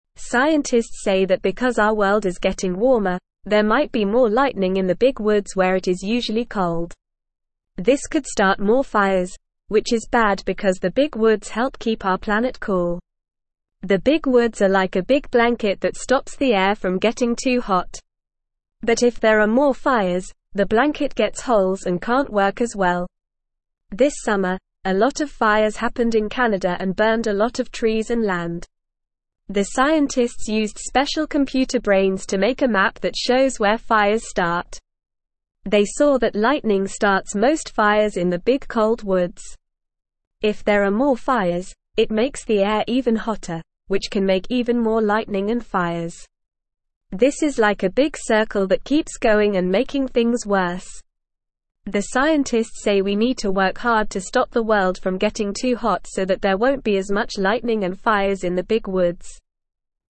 Normal
English-Newsroom-Beginner-NORMAL-Reading-More-Lightning-and-Fires-in-Cold-Woods.mp3